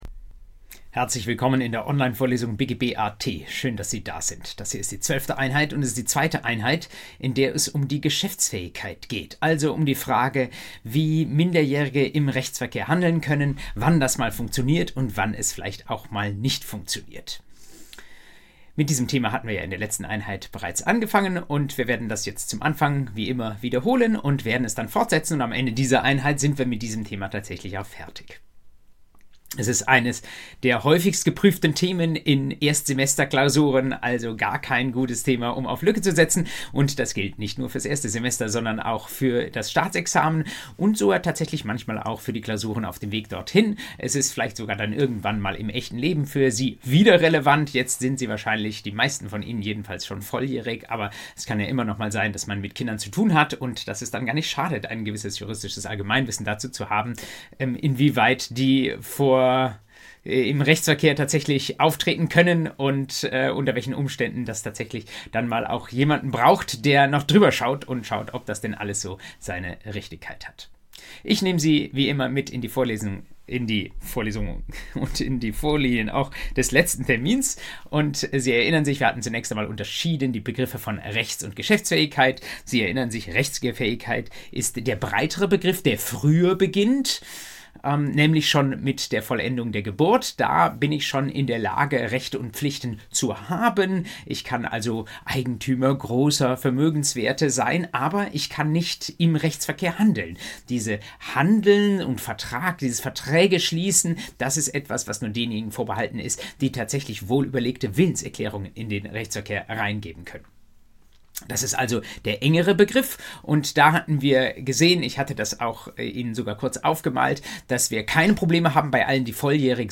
BGB AT Folge 12: Geschäftsfähigkeit II ~ Vorlesung BGB AT Podcast